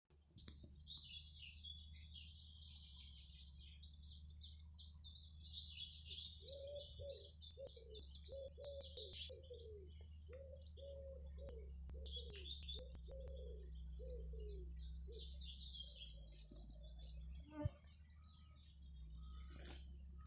Grasshopper Warbler, Locustella naevia
StatusSinging male in breeding season
NotesGrāvja un pļavas robežkrūmos, klusi